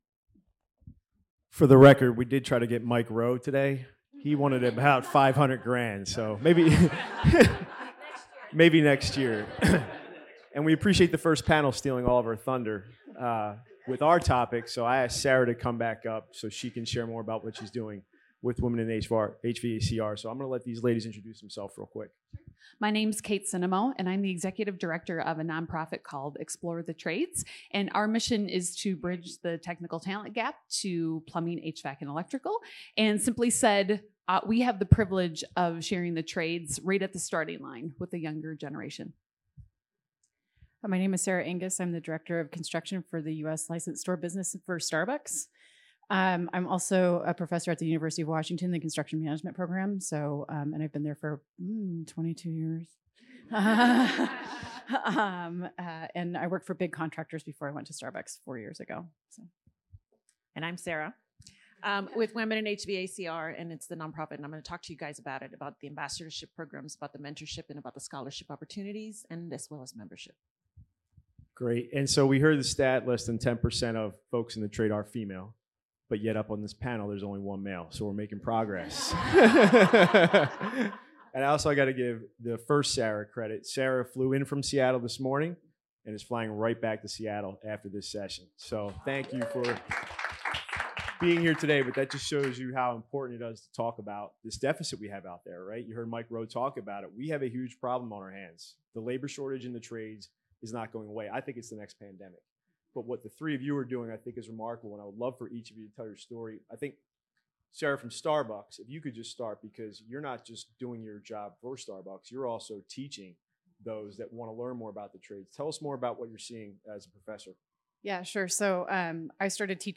Industry leaders at NEST’s IFM Summit session discussed the labor shortage in the tradesーand what can be done about it.